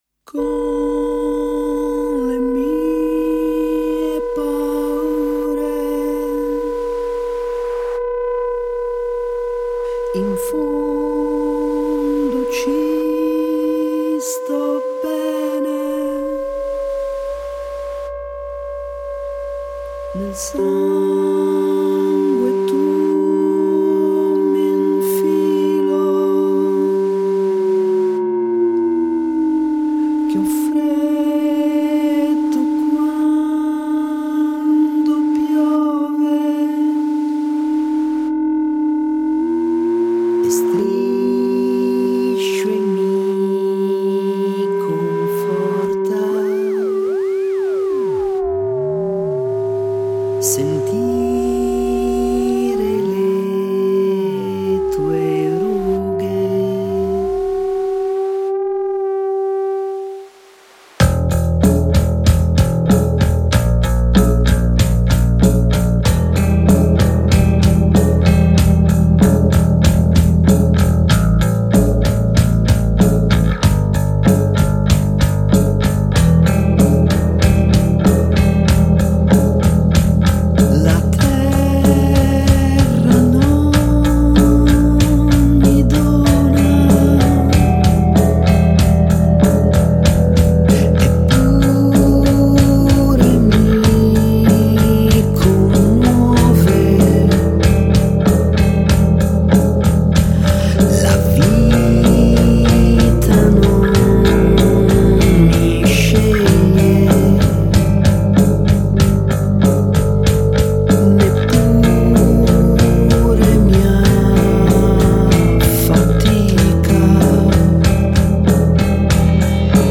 Luogo esecuzioneLOTOSTUDIO RA
GenerePop